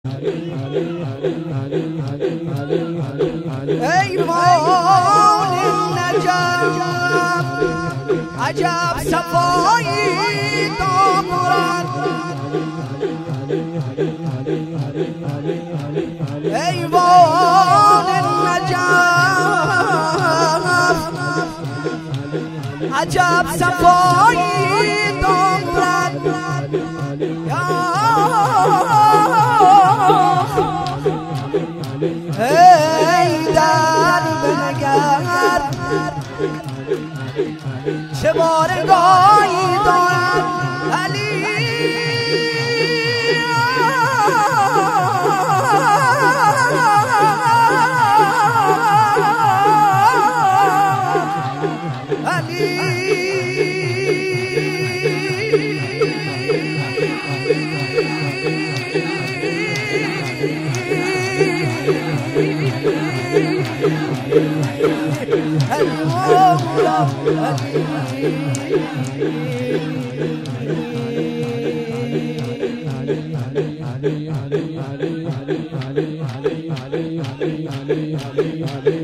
شعرخوانی در مدح امیرالمومنین
مراسم هفتگی مجمع رهروان حضرت زینب س